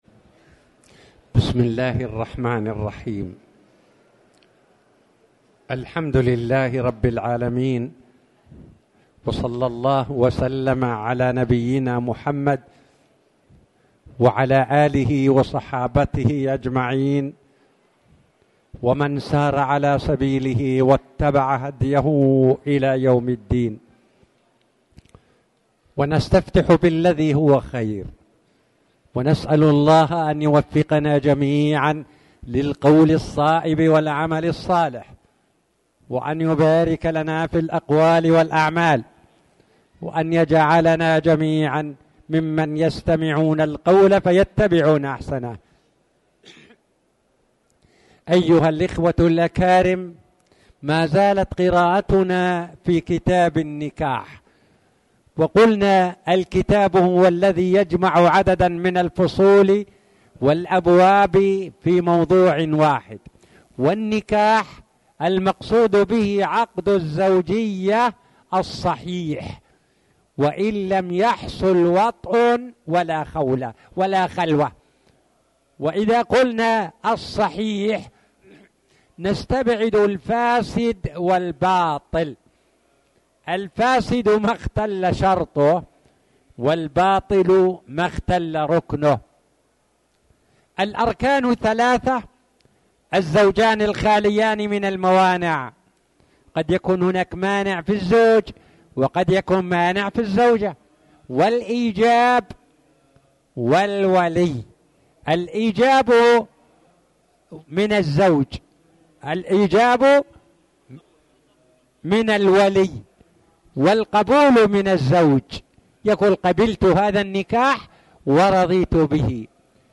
تاريخ النشر ٢٠ صفر ١٤٣٨ هـ المكان: المسجد الحرام الشيخ